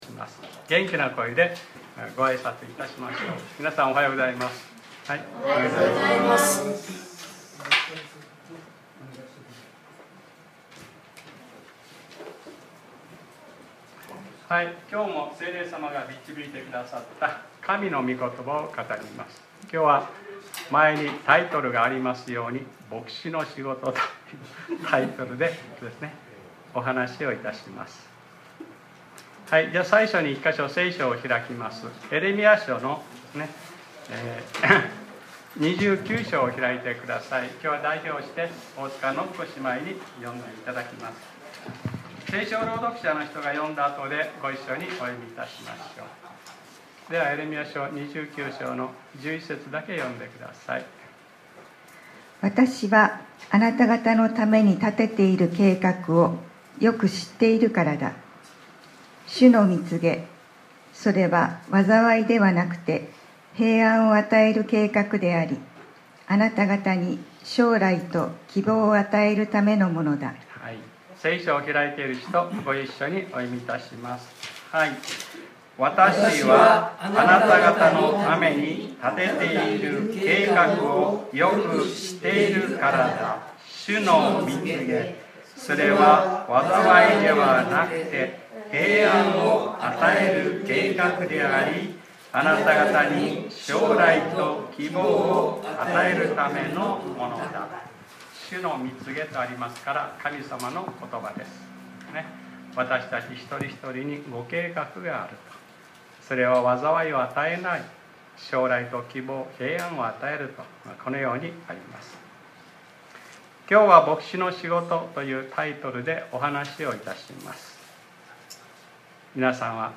2025年07月06日（日）礼拝説教『 牧師のお仕事 』 | クライストチャーチ久留米教会